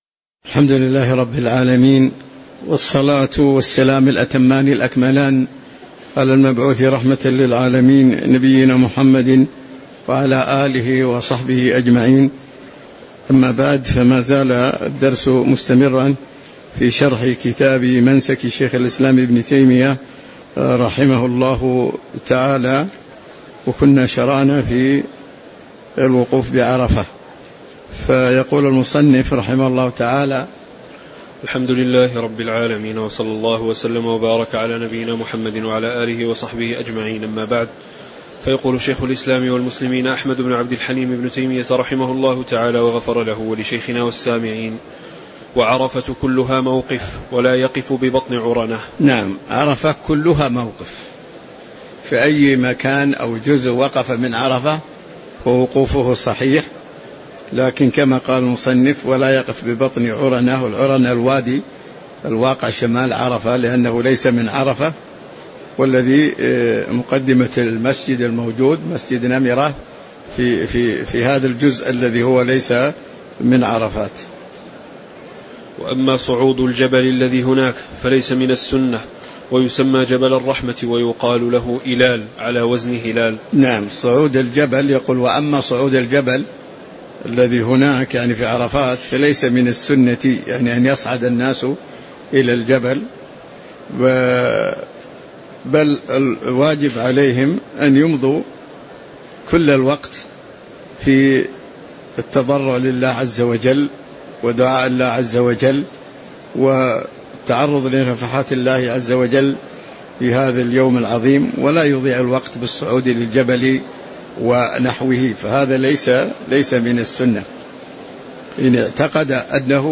تاريخ النشر ٢ ذو الحجة ١٤٤٦ هـ المكان: المسجد النبوي الشيخ